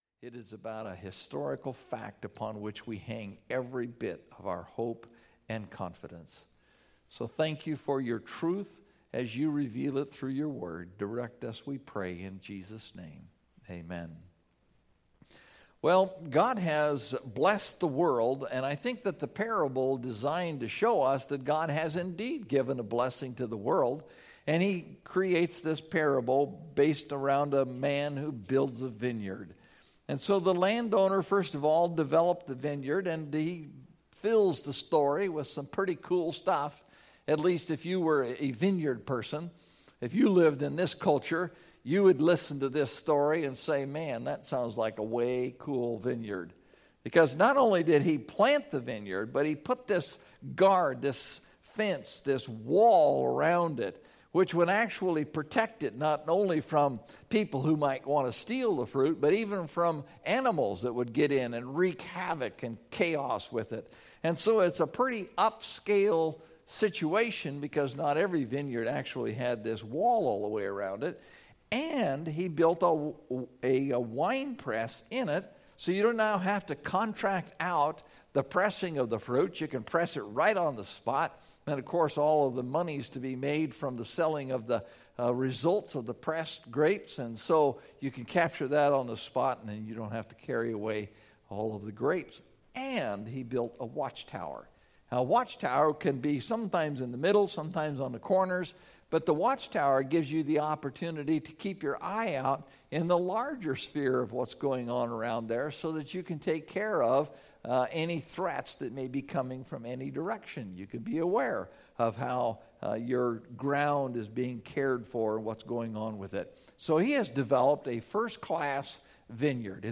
Christmas Message